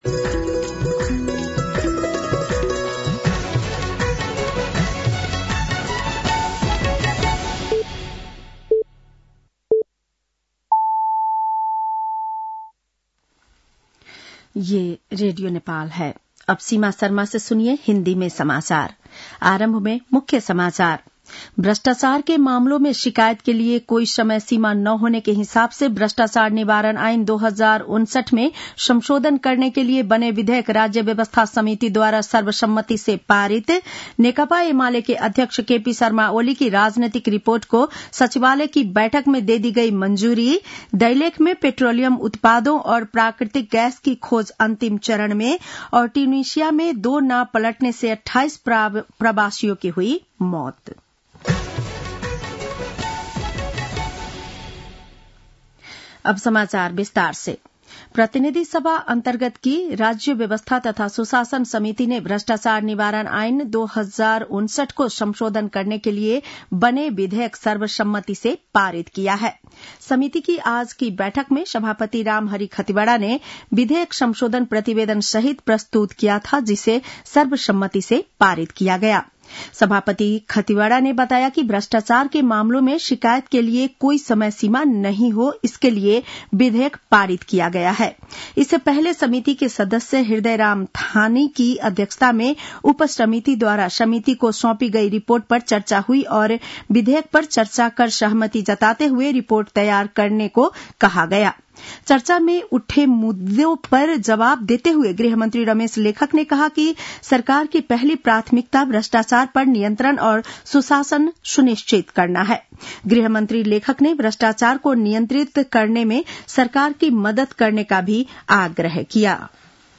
बेलुकी १० बजेको हिन्दी समाचार : १९ पुष , २०८१
10-PM-Hindi-NEWS-9-18.mp3